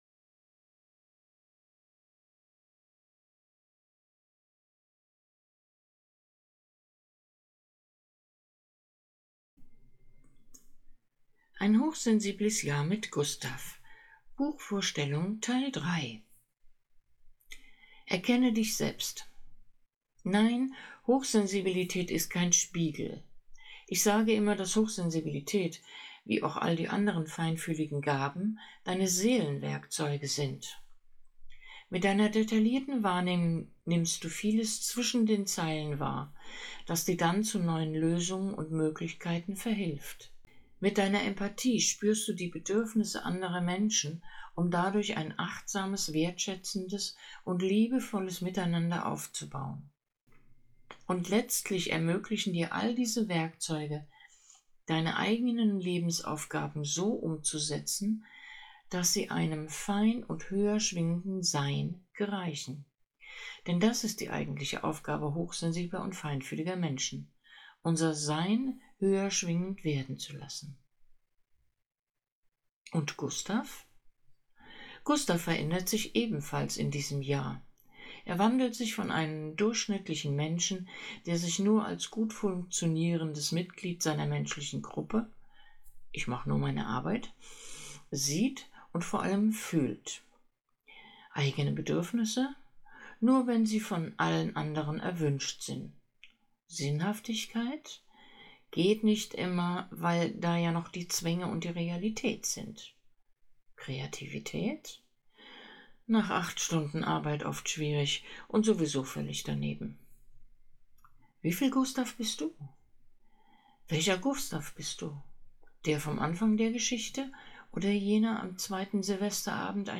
Audio-Buchtipp "Ein hochsensibles Jahr mit Gustav" - Teil 3